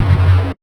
Lo Fi Noize Lo Pitch.wav